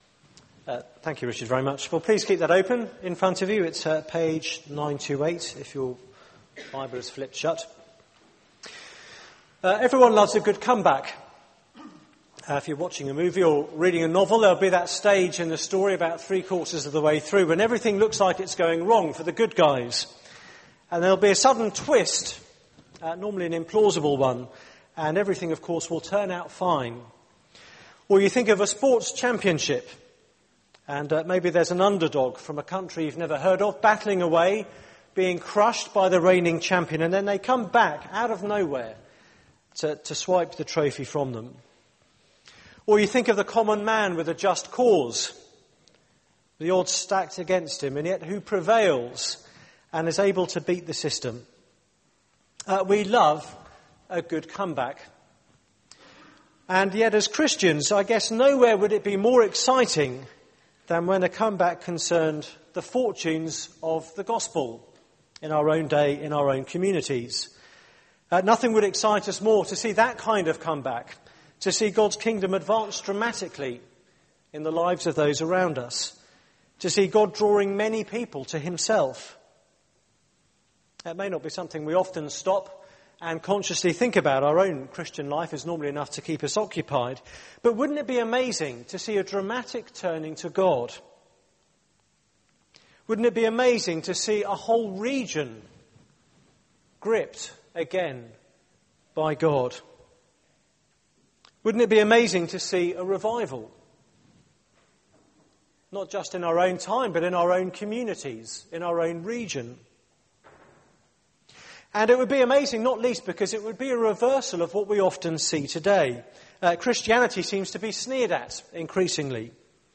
Media for 9:15am Service on Sun 23rd Jan 2011 09:15 Speaker
Theme: The Compassionate Lord Sermon